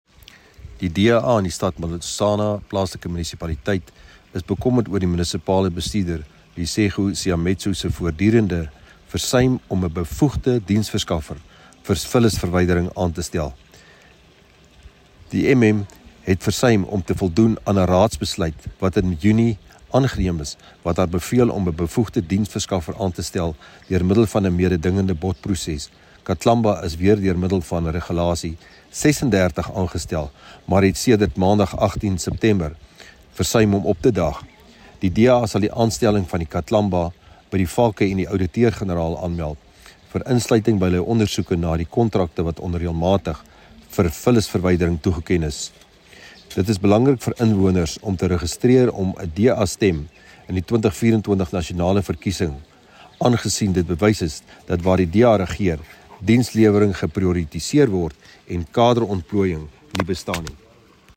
Note to Broadcasters: Please find linked soundbites in English and